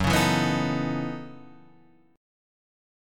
F#m13 chord